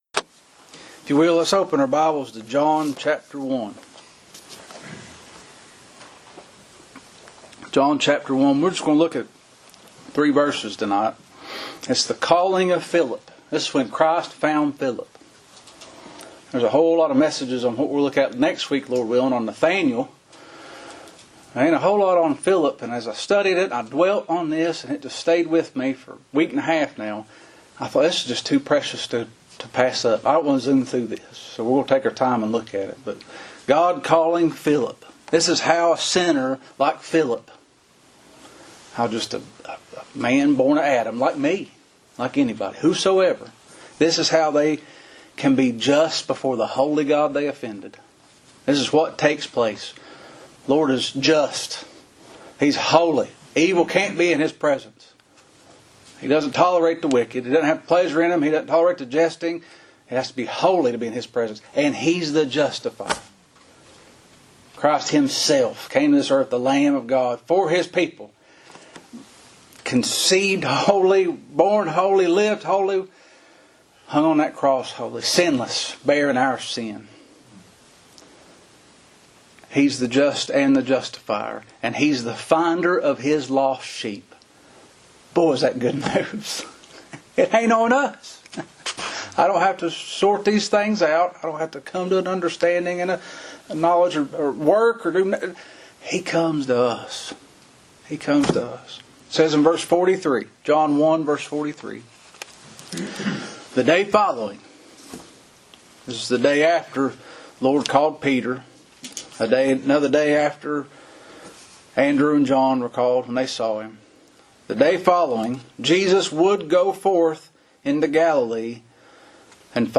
Christ Found Philip | SermonAudio Broadcaster is Live View the Live Stream Share this sermon Disabled by adblocker Copy URL Copied!